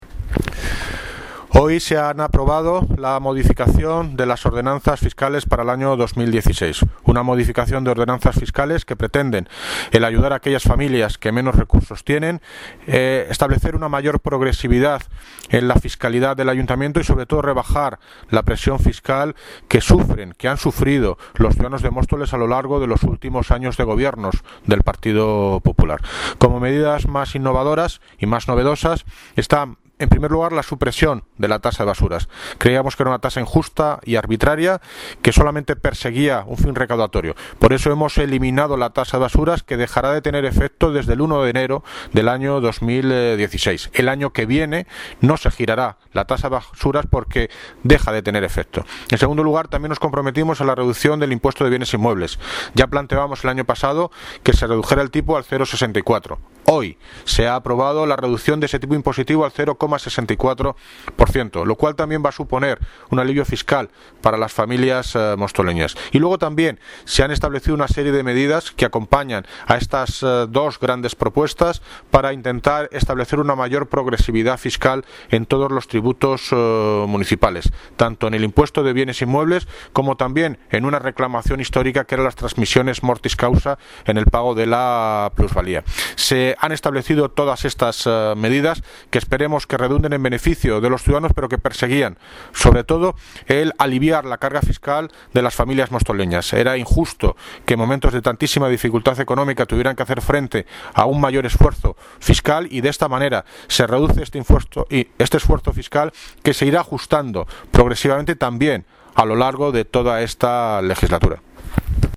Audio - David Lucas (Alcalde de Móstoles) Sobre ordenanzas fiscales 2016
Audio - David Lucas (Alcalde de Móstoles) Sobre ordenanzas fiscales 2016.mp3